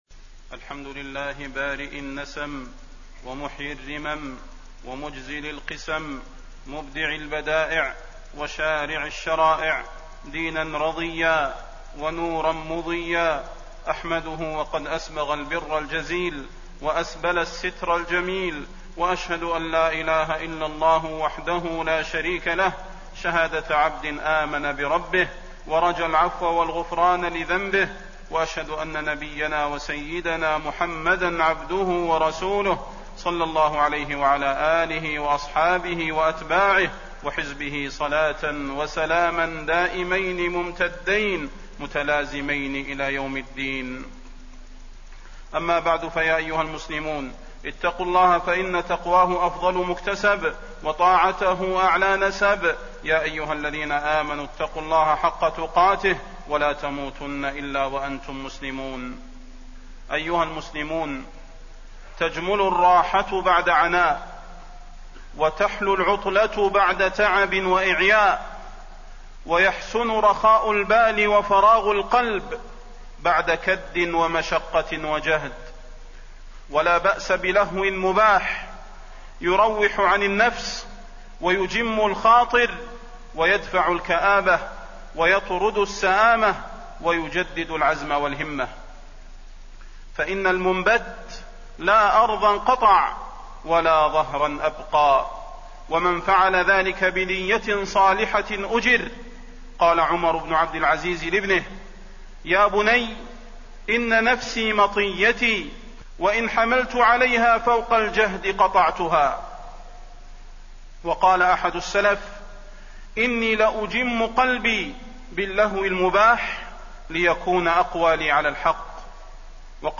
تاريخ النشر ٢٢ رجب ١٤٣٢ هـ المكان: المسجد النبوي الشيخ: فضيلة الشيخ د. صلاح بن محمد البدير فضيلة الشيخ د. صلاح بن محمد البدير الشباب والأجازة